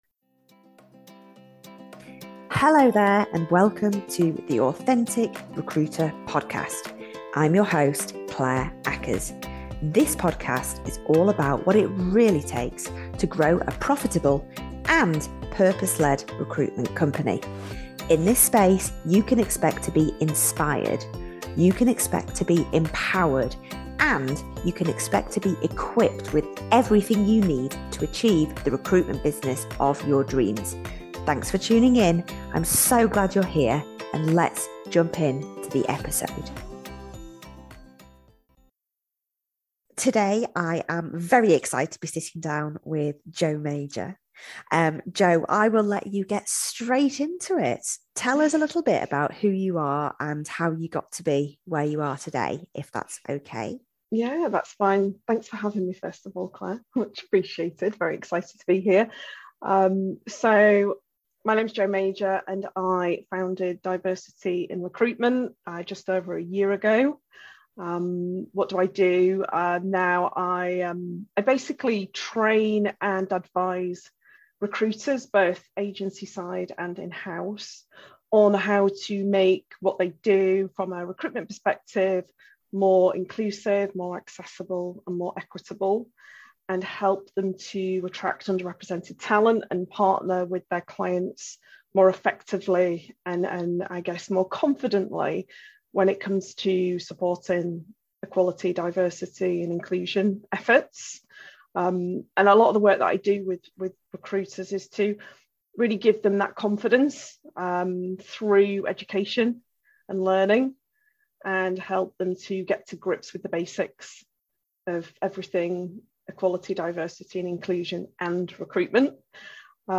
Diversity & Inclusion in Recruitment: Interview